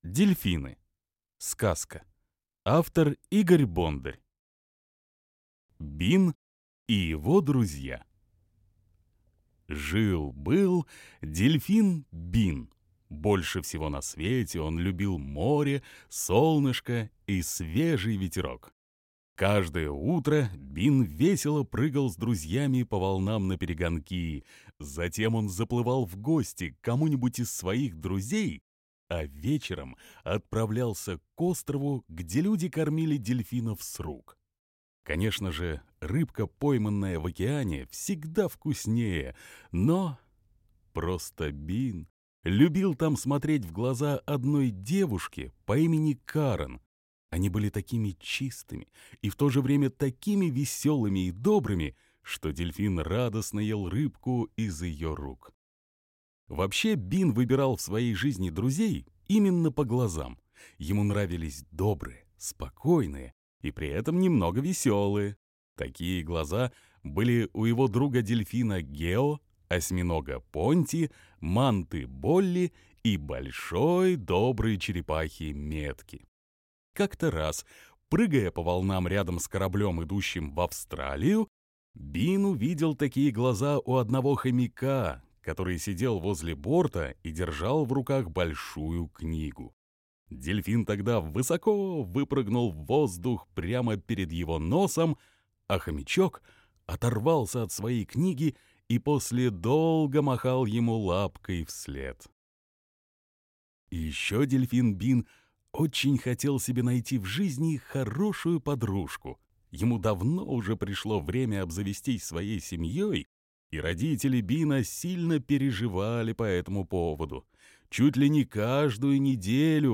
Дельфины - аудиосказка